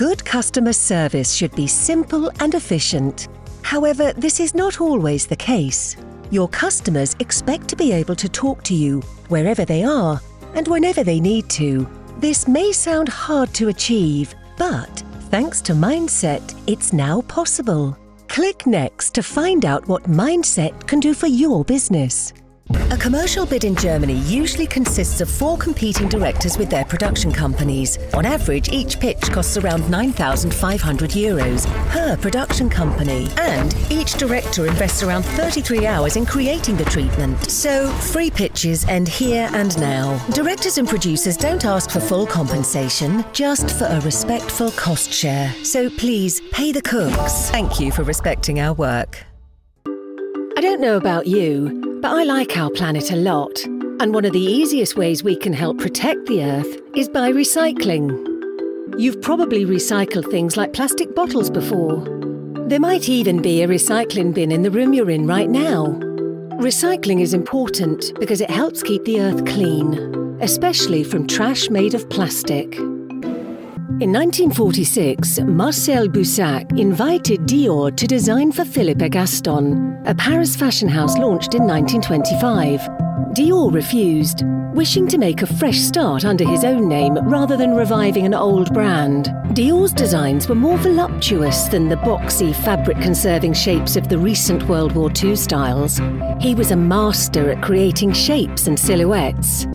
English (British)
Warm, Friendly, Versatile, Natural, Mature
Corporate